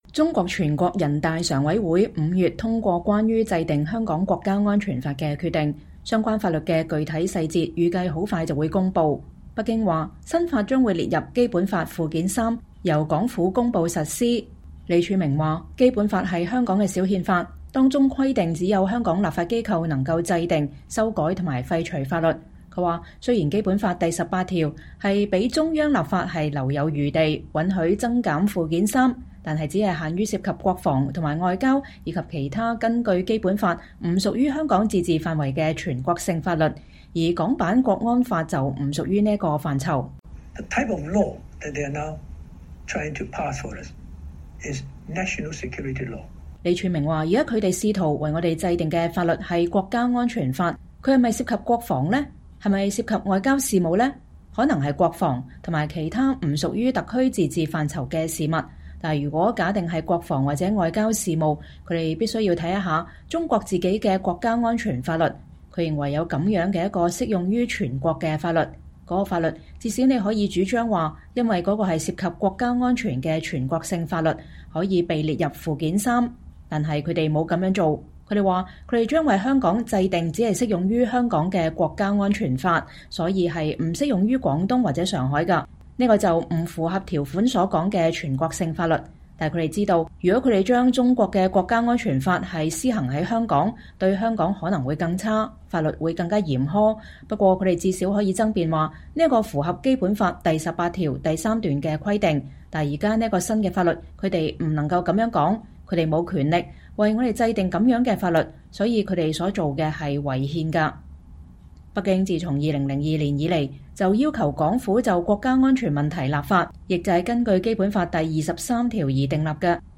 專訪李柱銘：為什麼“港版國安法”違反《基本法》